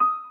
piano_last11.ogg